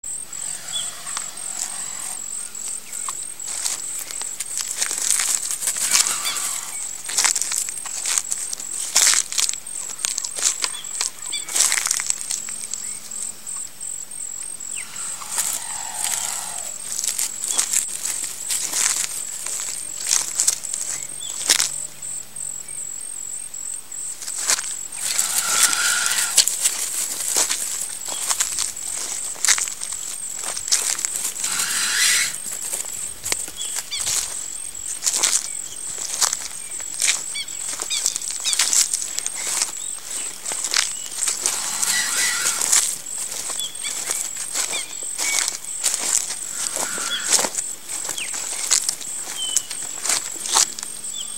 Звуки муравьеда
Звук муравьеда в лесу его шипение